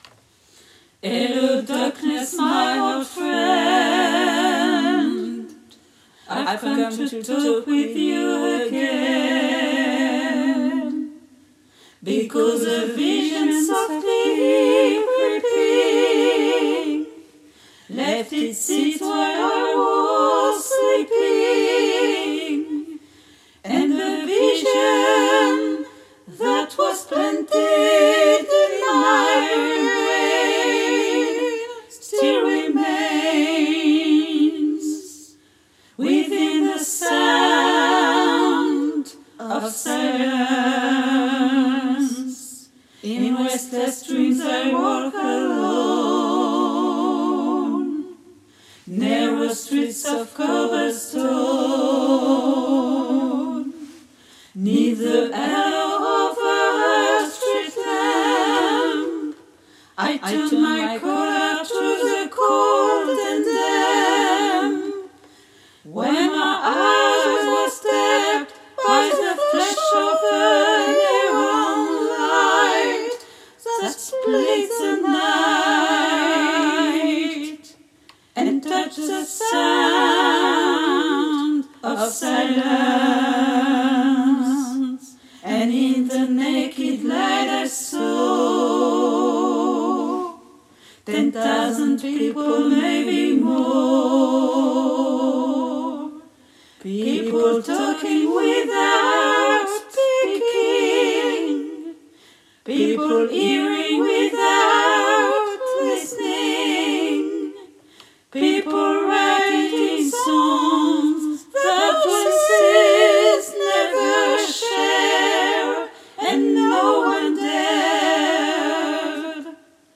MP3 versions chantées
Trois voix